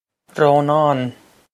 Rónán Roh-nahn
This is an approximate phonetic pronunciation of the phrase.